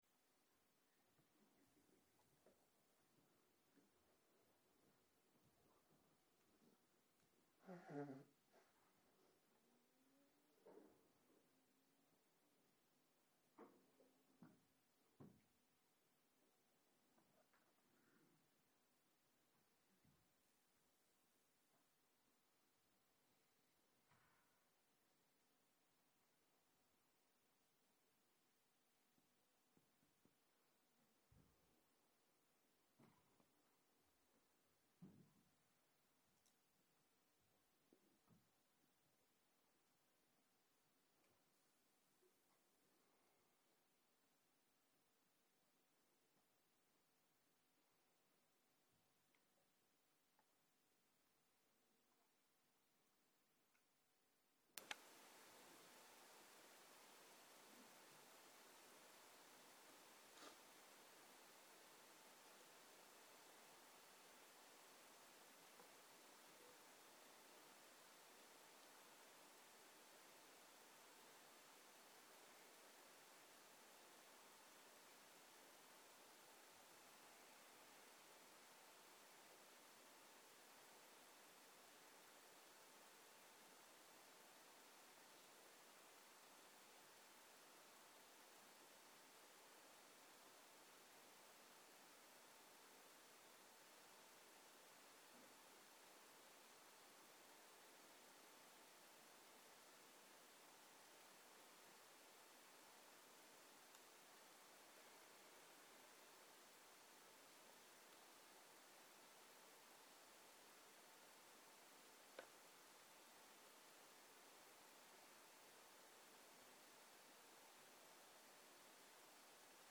ערב - שיחת דהרמה - החיים, שיפוצים ונקיונות
סוג ההקלטה: שיחות דהרמה